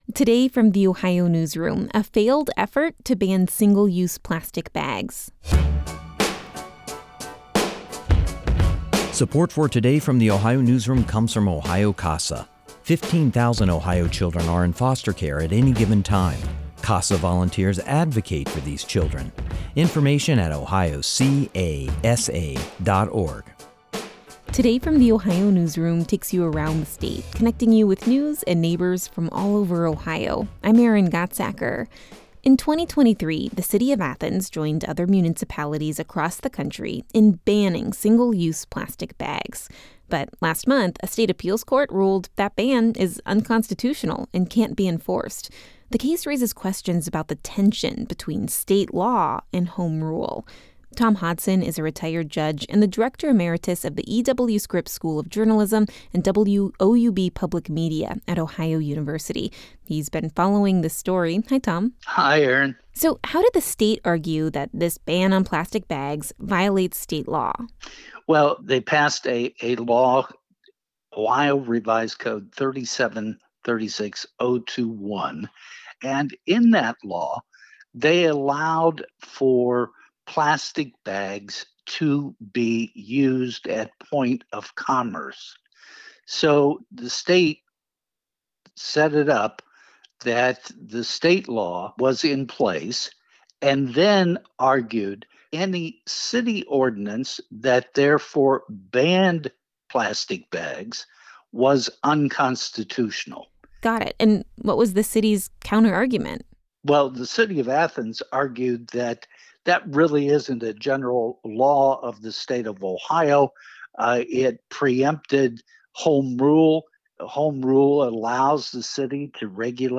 This interview has been edited for brevity and clarity.